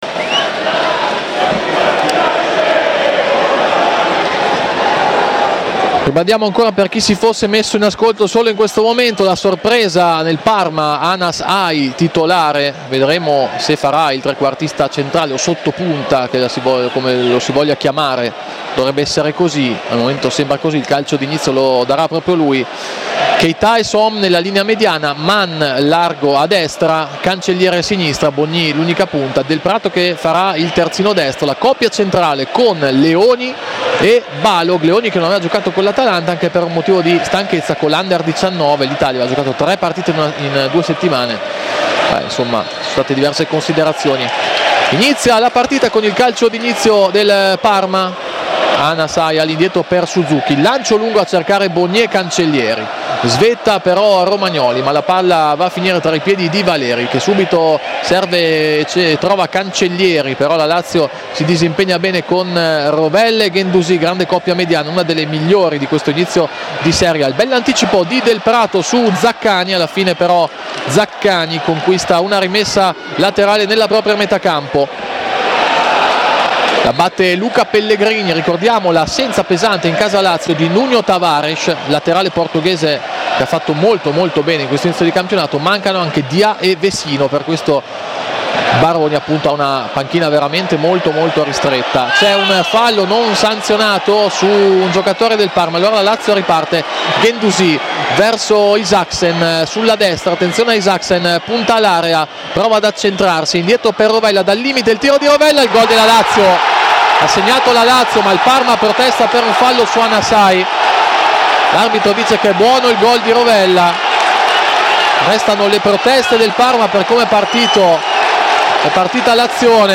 Radiocronache Parma Calcio Parma - Lazio 1° tempo - 1° dicembre 2024 Dec 01 2024 | 00:53:56 1x 00:00 / 00:53:56 Subscribe Share RSS Feed Share Link Embed